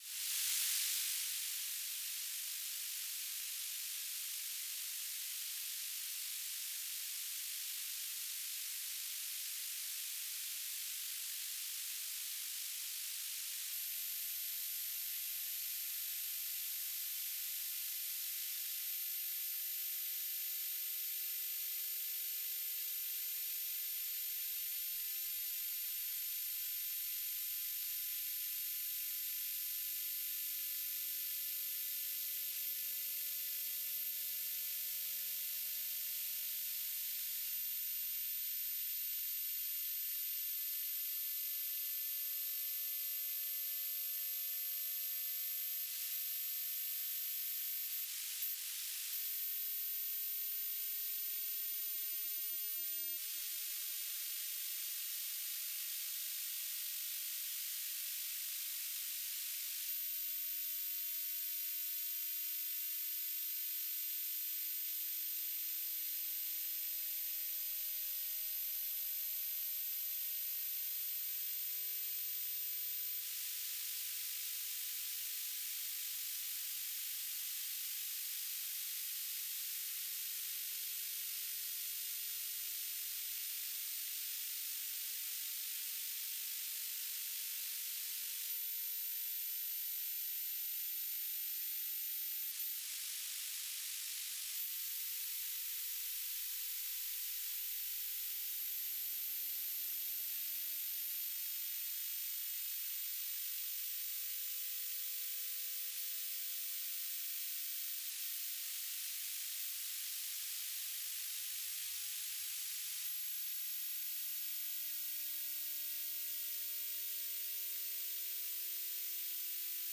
"transmitter_description": "BPSK1k2 AX.25 TLM",
"transmitter_mode": "BPSK",